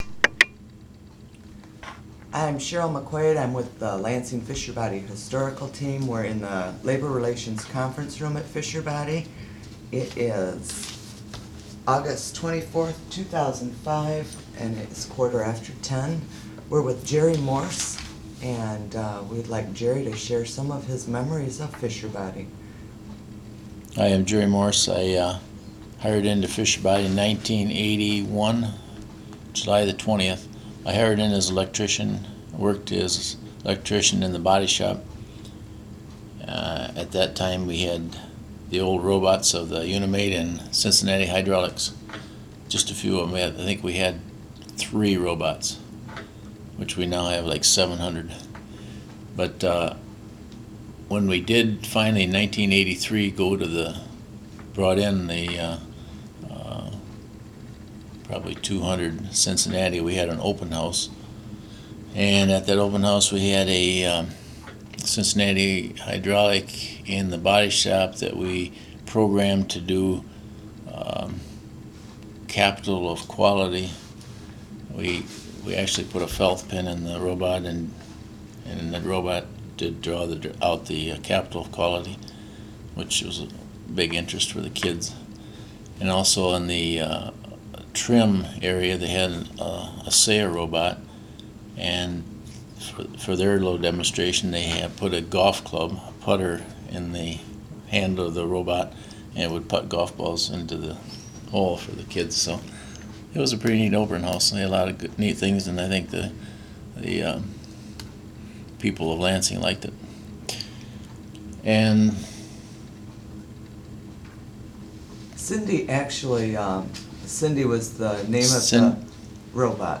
United Auto Workers Local 602/General Motors Oral History Project